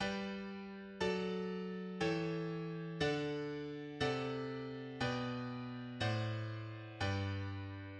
Rule of the octave ascending in G major, in 1st position.
Rule of the octave descending in G major, in 1st position.